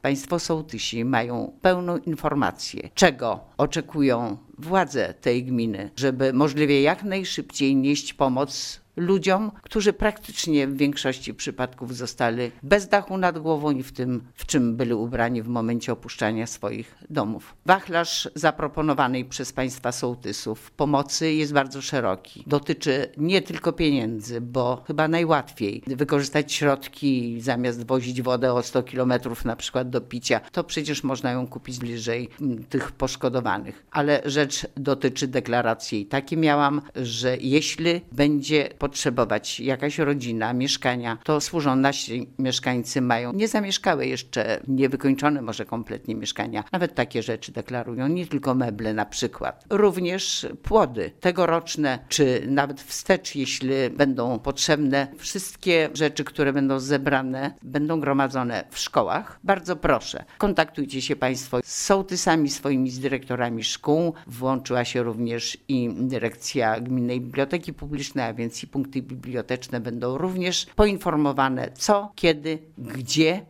Organizację zbiórek darów oraz innych form wsparcia władze gminy ustaliły z sołtysami oraz dyrektorami szkół, którzy będą prowadzic zbiórki w poszczególnych miejscowościach – informuje wójt Gminy Łuków Kazimiera Goławska: